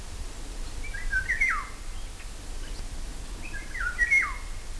Vive nel bosco a non più di 100 metri da noi; non ama farsi vedere, ma si fa sentire fischiando un
ritornello (200 KB) forte e monotono.
rigogolo.wav